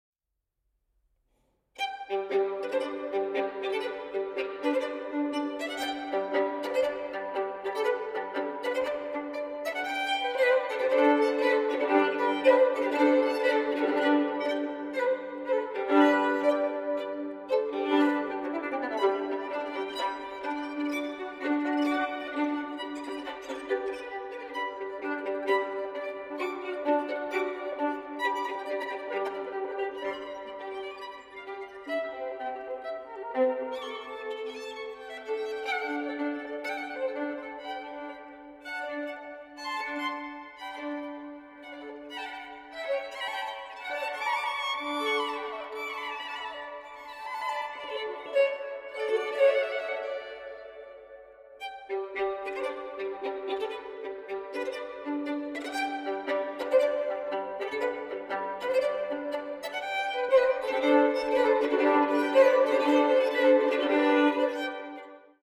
• Genres: Baroque, Classical, Strings